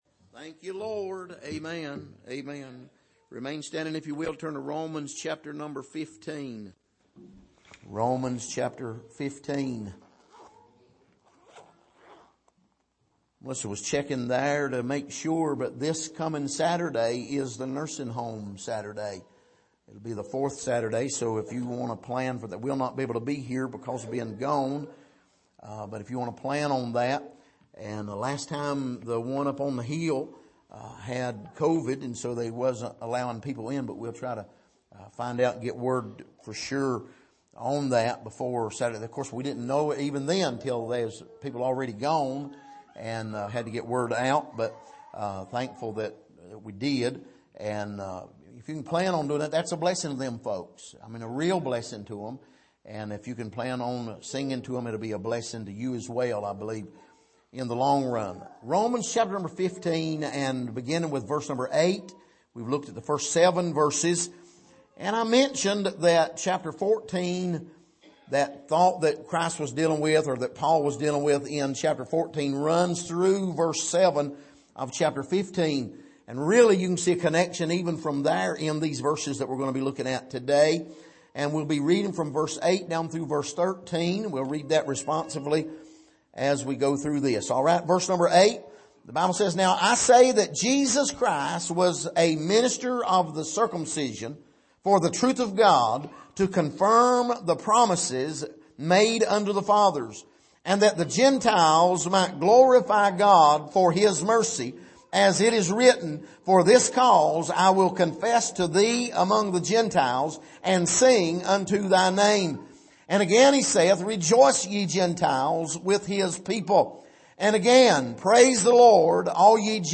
Passage: Romans 15:8-13 Service: Sunday Morning